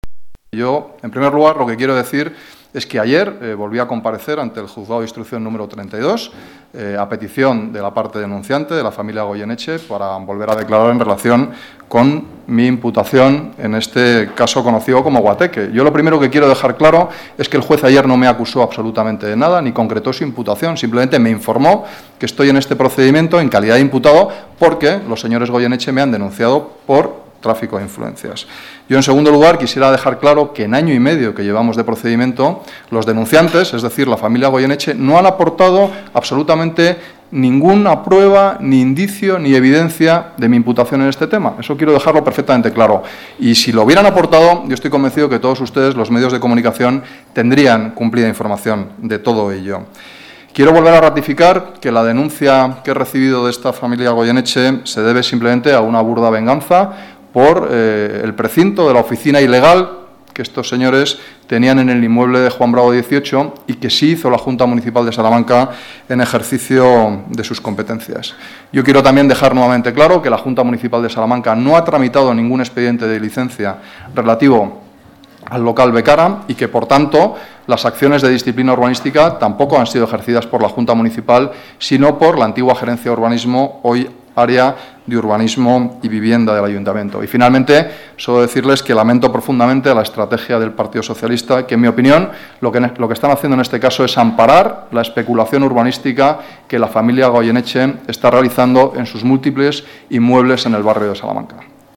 Nueva ventana:Declaraciones concejal Salamanca, Íñigo Henríquez de Luna: explicaciones caso Becara